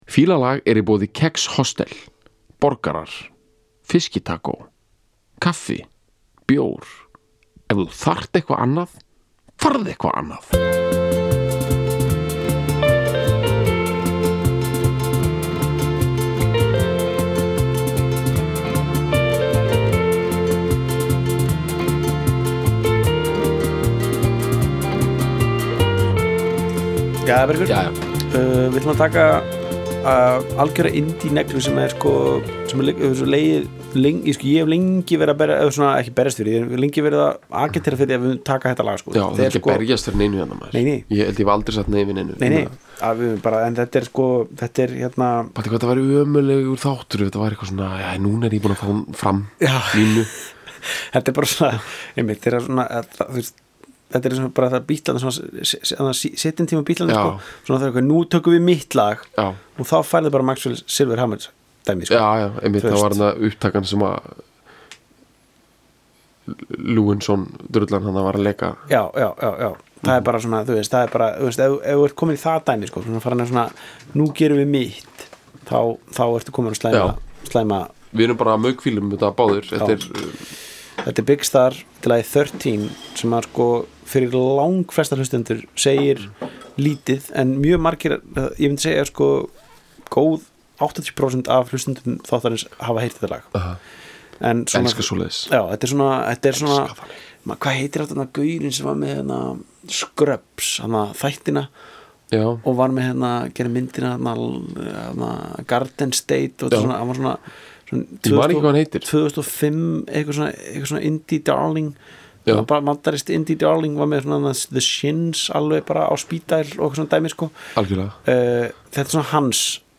Það er soft-trigger-warning á þessum þætti Fílalags, eða að minnsta kosti laginu sem spilað er í lokin.
Bandarískur kassagítar mulningur frá 1972, sem fangar angurværðina alla.